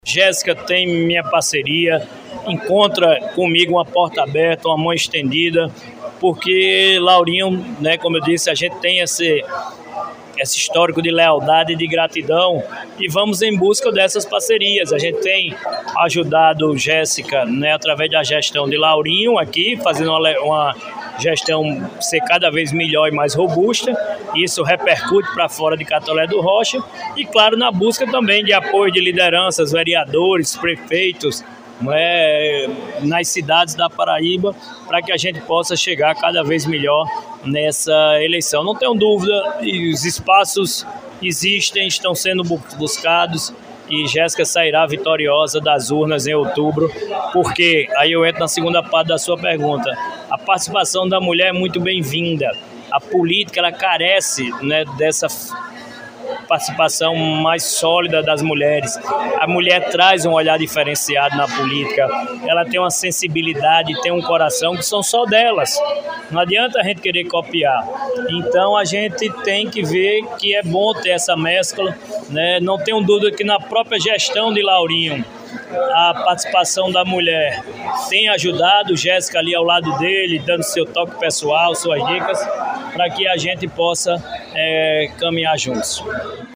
Durante as comemorações pelos 190 anos de emancipação política de Catolé do Rocha, realizadas no último domingo (25), o senador Efraim Filho (União Brasil) marcou presença e, em entrevista à imprensa local, fez importantes declarações sobre o cenário político estadual.